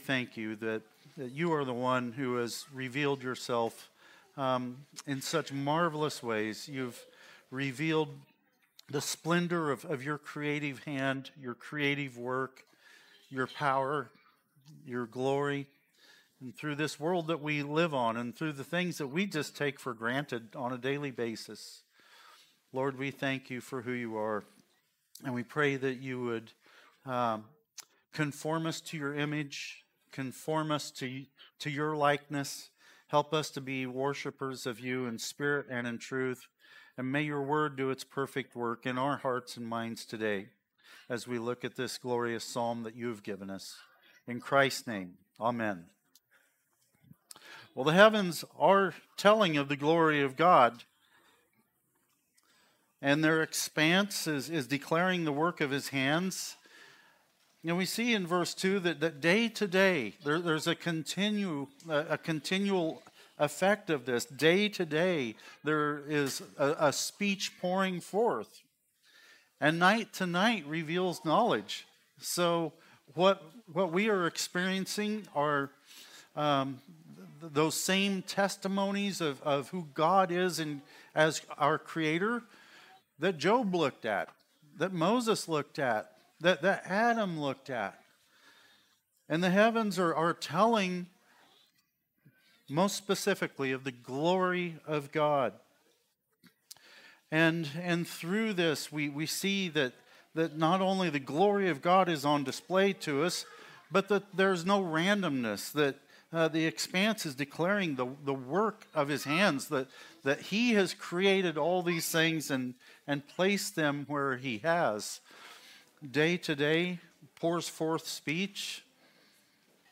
Christ the Redeemer Church | Sermon Categories Knowing God